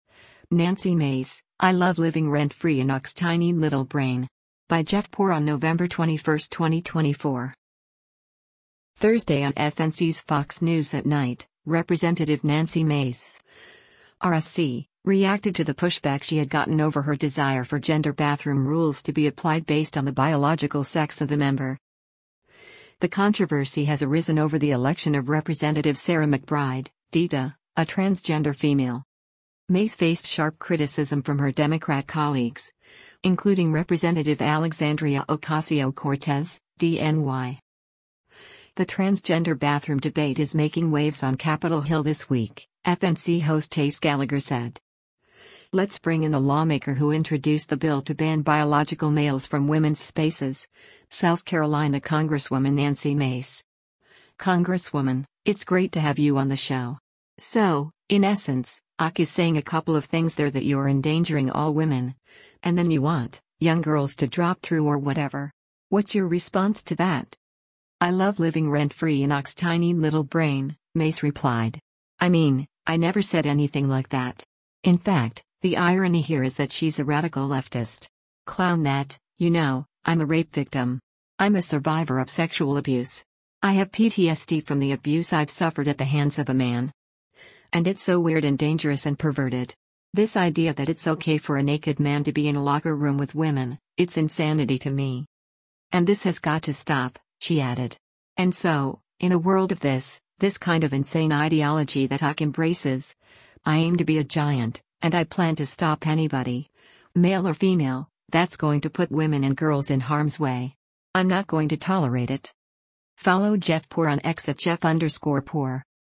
Thursday on FNC’s “Fox News @ Night,” Rep. Nancy Mace (R-SC) reacted to the pushback she had gotten over her desire for gender bathroom rules to be applied based on the biological sex of the member.